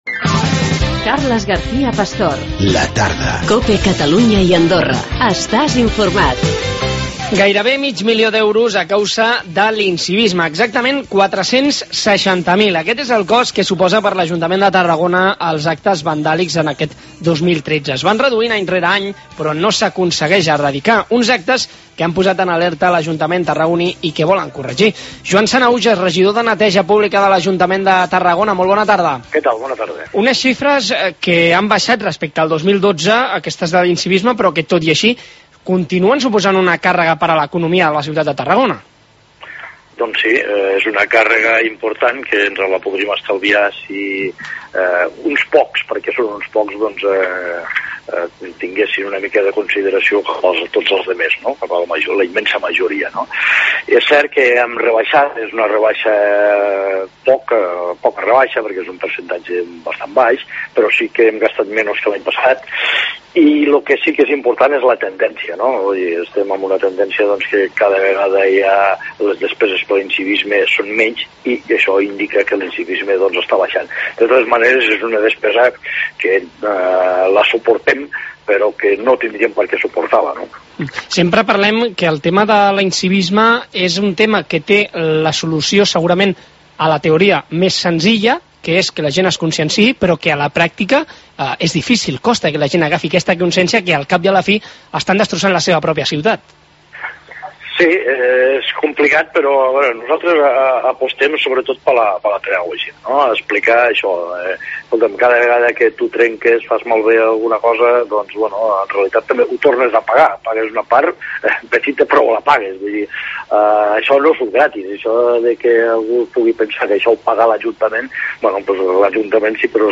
460 mil euros és el cost que li suposa a l'Ajuntament de Tarragona l'incivisme i actes vandàlics. Hem parlat amb Joan Sanahujes, regidor de neteja pública